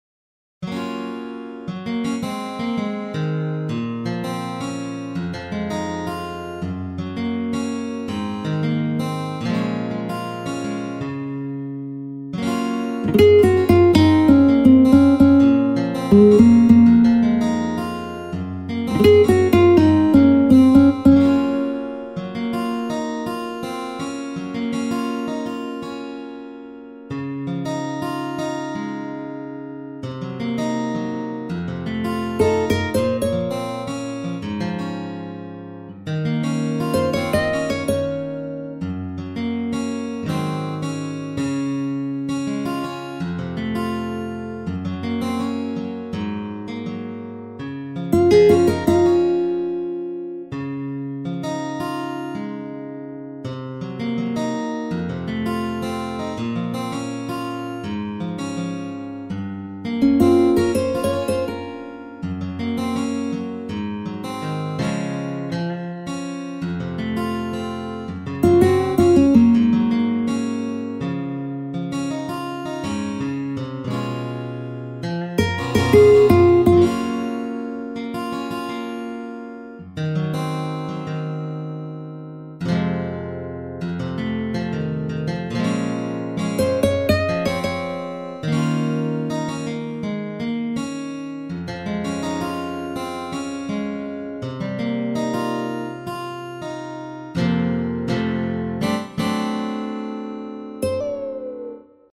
HALion6 : A.Guitar
SR West Coast Gtr